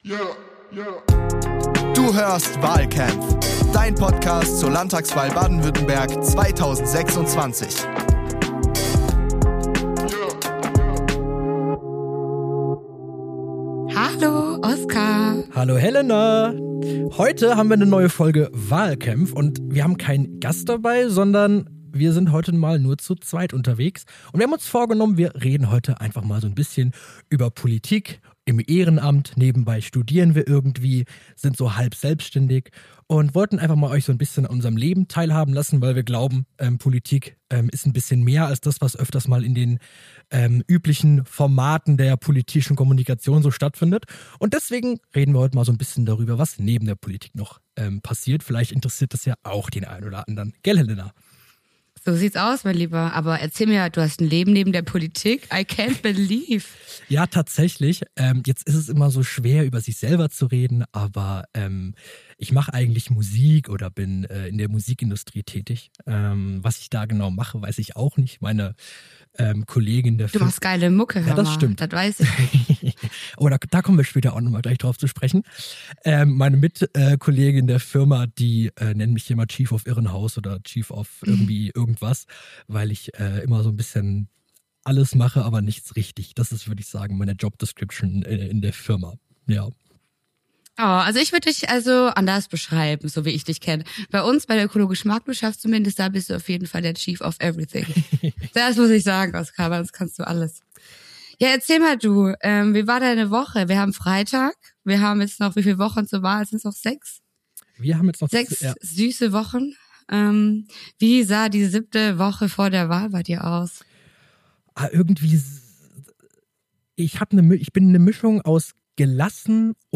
In dieser Folge schließen wir uns im Studio ein und lassen die Filter fallen.
Eine Folge wie ein Feierabendbier nach dem Infostand: Ehrlich, direkt und entspannt.